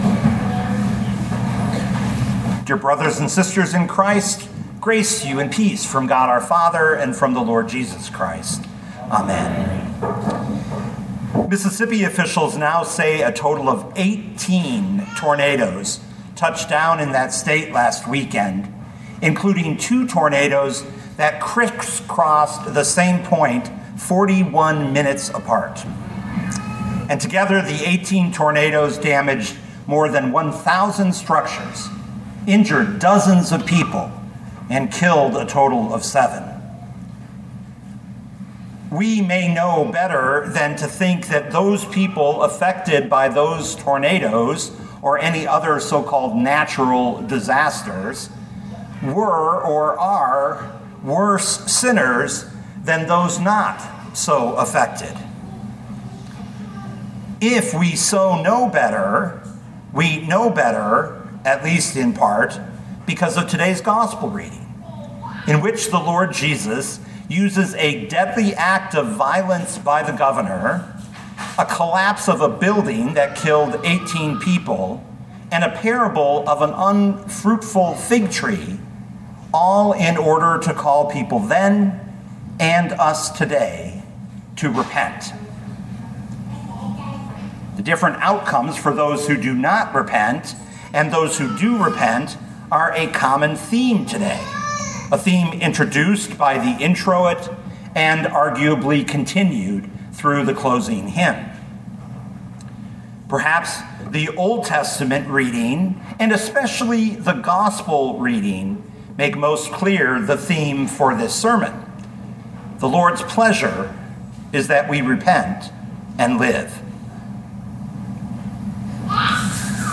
2025 Luke 13:1-9 Listen to the sermon with the player below, or, download the audio.